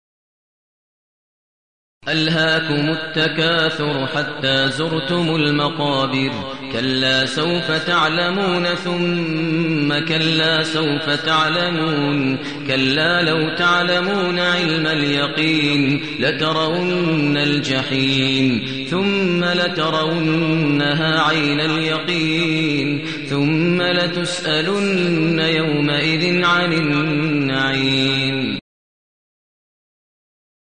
المكان: المسجد الحرام الشيخ: فضيلة الشيخ ماهر المعيقلي فضيلة الشيخ ماهر المعيقلي التكاثر